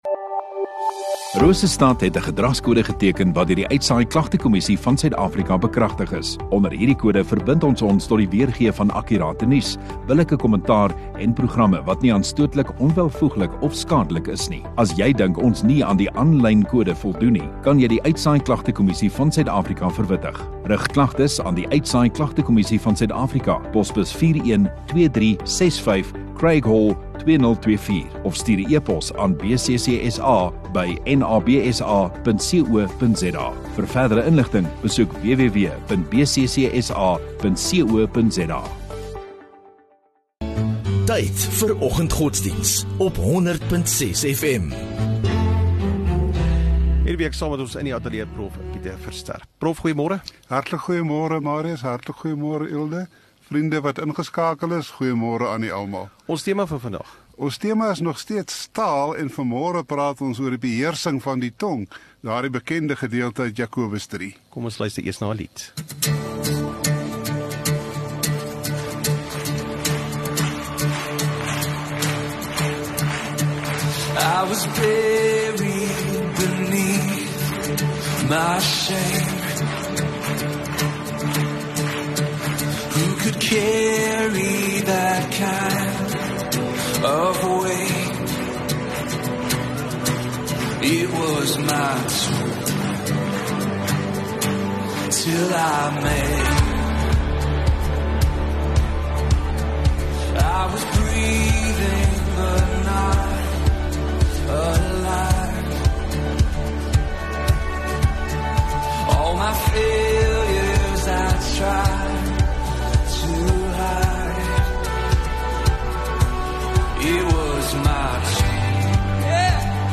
7 Aug Woensdag Oggenddiens